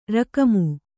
♪ rakamu